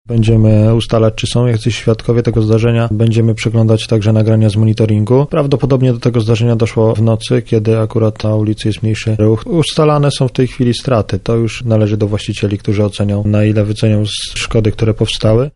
O tym, na jakim etapie jest sprawa – mówi aspirant